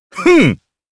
Kain-Vox_Attack2_jp.wav